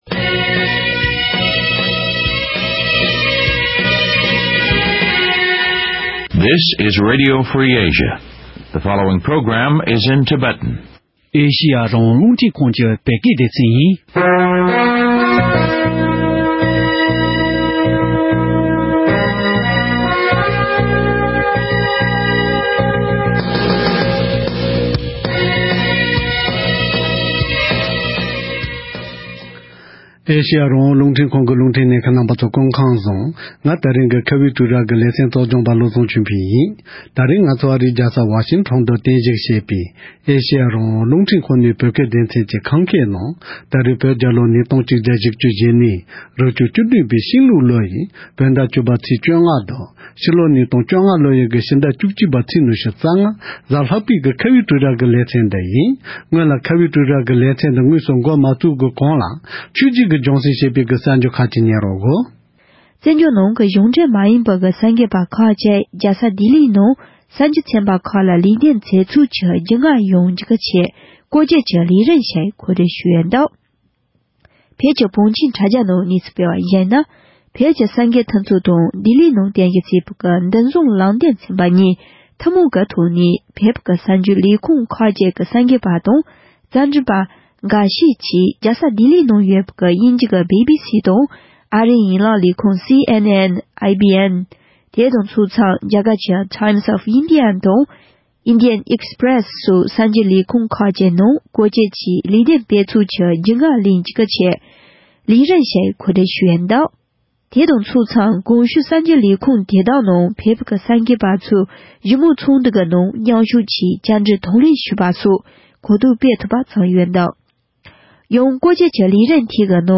གནད་དོན་དེའི་སྐོར་རྒྱལ་བ་མདོ་ཁམས་པ་མཆོག་ལ་ཐད་ཀར་ཞལ་པར་ཐོག་བཅར་འདྲི་ཞུས་པ་དེ་གསན་རོགས་གནང་།